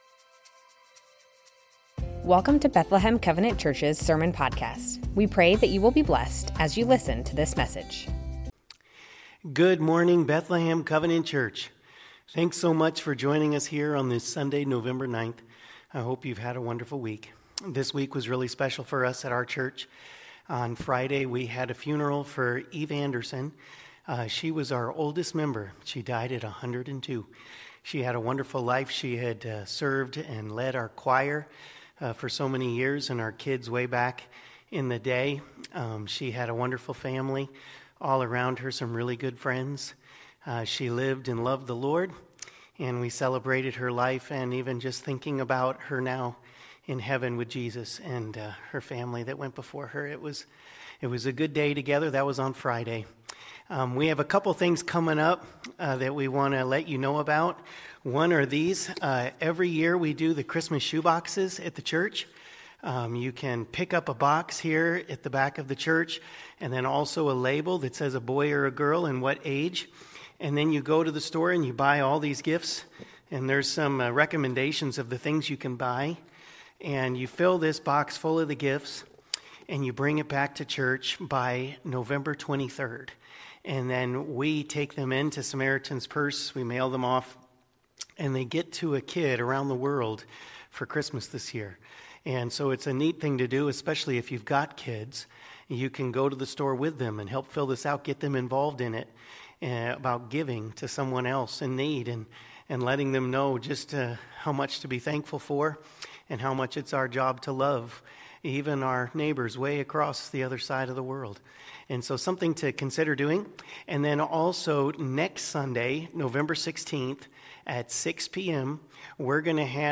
Bethlehem Covenant Church Sermons The Names of God - Immanuel Nov 10 2025 | 00:35:29 1x 00:00 / 00:35:29 Subscribe Share Spotify RSS Feed Share Link Embed